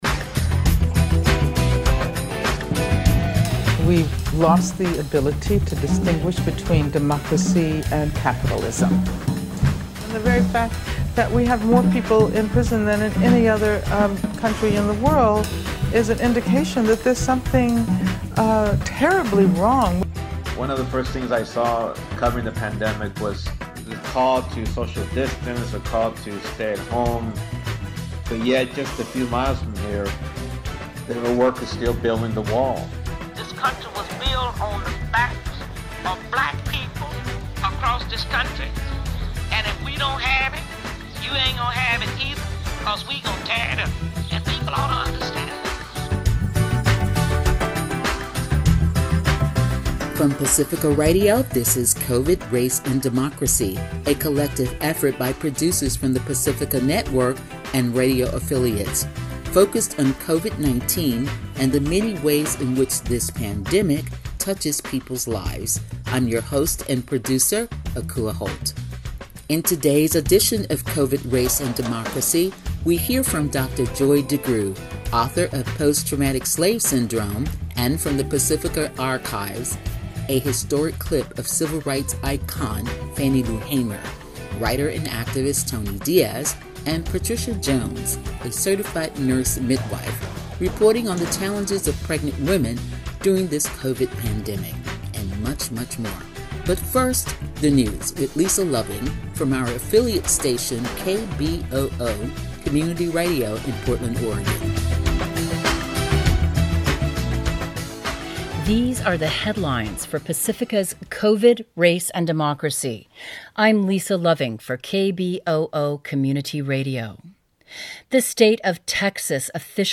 Today’s edition of Covid, Race and Democracy will feature a clip of renowned educator and researcher Dr. Joy DeGruy, author of Post Traumatic Slave Syndrome.
From the Pacifica archives a historic interview of Civil Rights icon Fannie Lou Hamer. Ms. Hamer speaks out regarding the state sponsored suppression of Black voters in 1964.